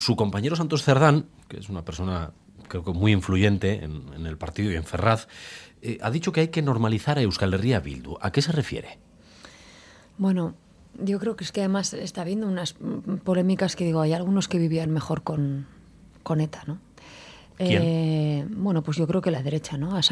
María Chivite levantó un revuelo notable el martes afirmando en Radio Euskadi que la derecha vivía mejor con ETA.